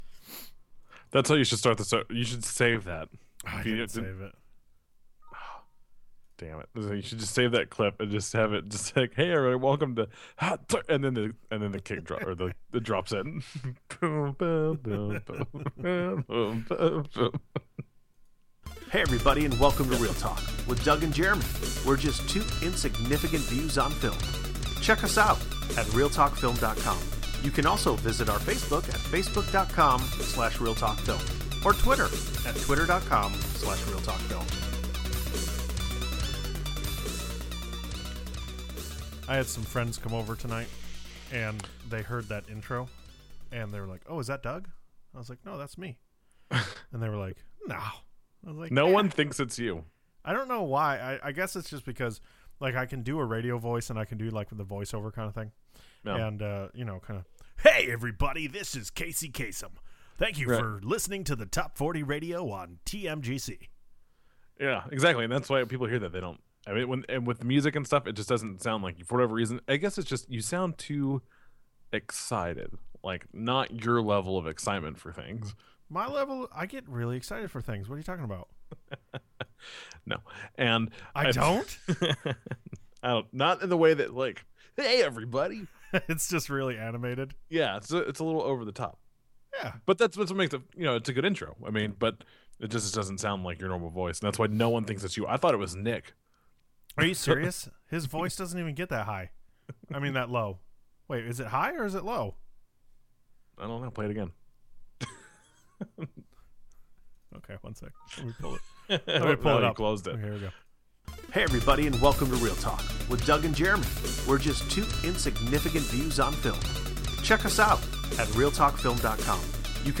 Enjoy our new episode where we can get enough of our English accents.